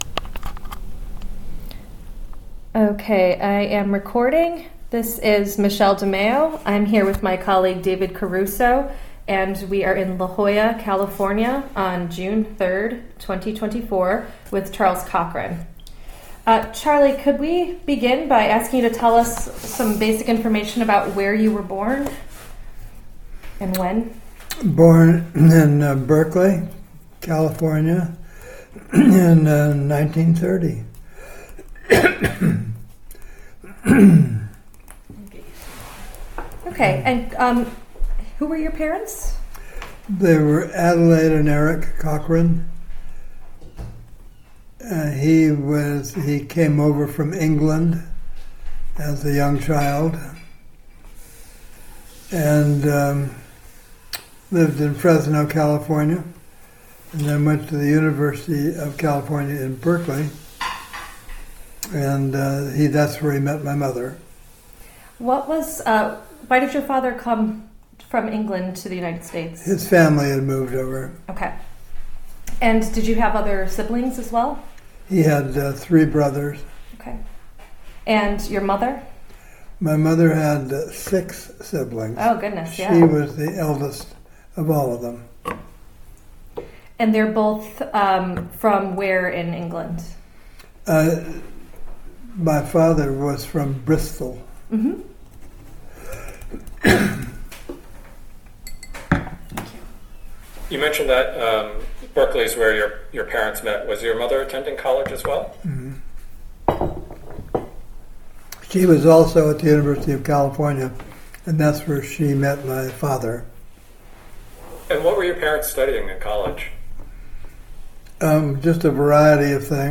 Oral histories